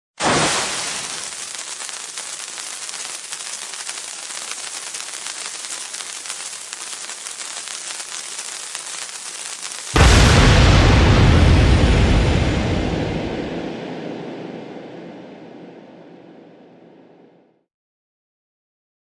Dynamite Fuse Burning & Explosion sound effects free download